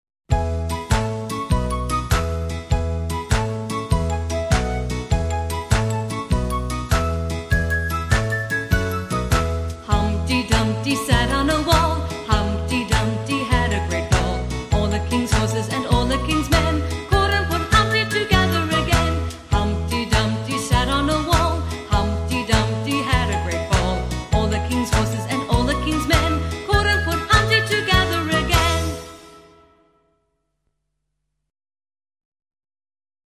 Humpty Dumpty - английская песня-шутка.
Песни-потешки